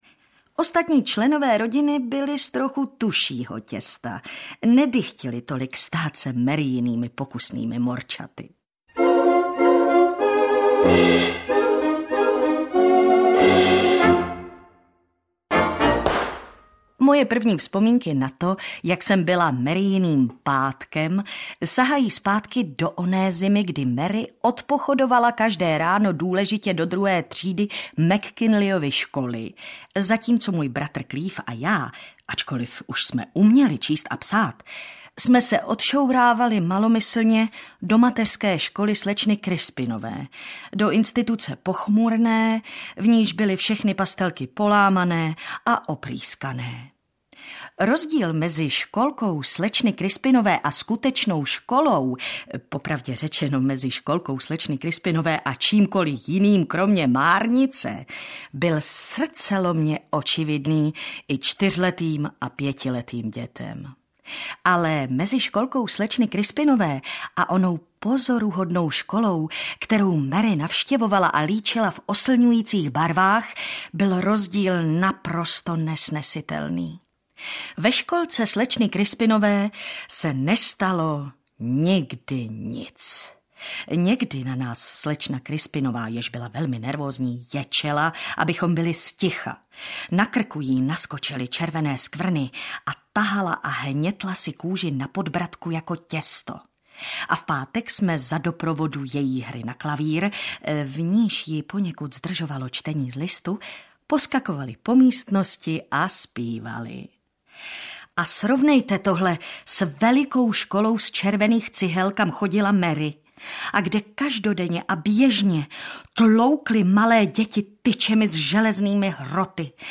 Audiobook
Read: Martina Hudečková